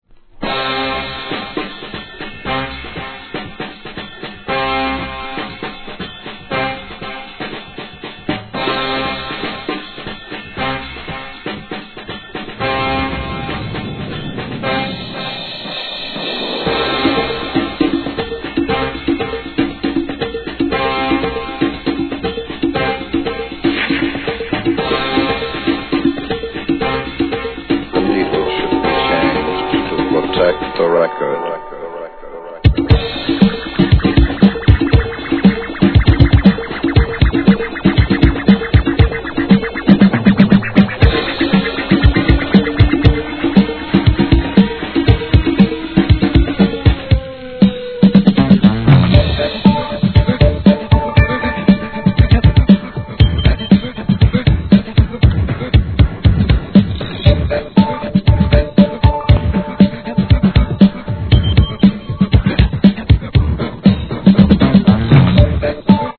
HIP HOP/R&B
OLD SCHOOLなブレイク物で、ダンサーにも重宝しそうです!!